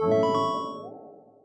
win.ogg